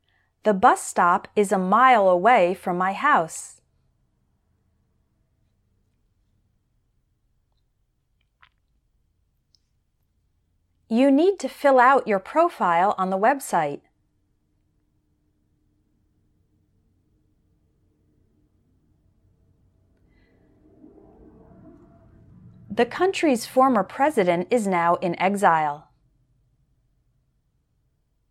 The two different ways to pronounce the -ILE ending in American English are “ay-ull” as in smile, and “ull” as in mobile. First let’s practice the “ay-ull” sound.